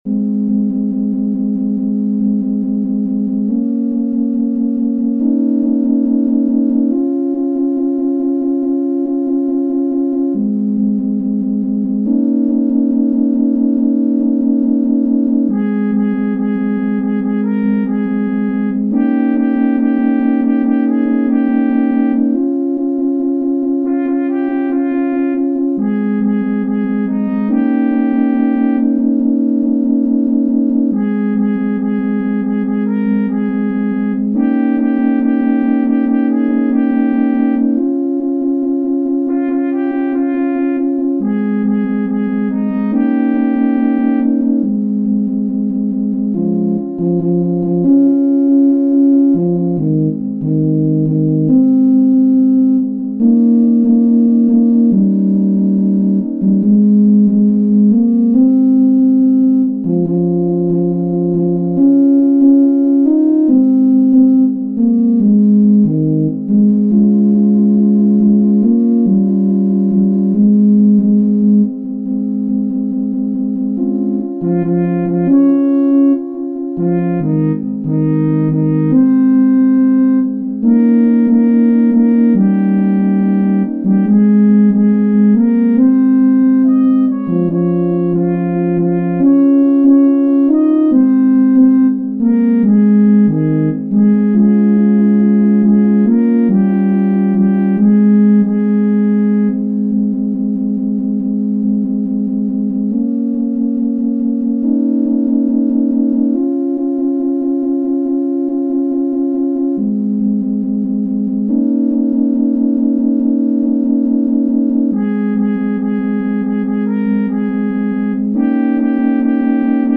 Une version simplifiée d’un monument du romantisme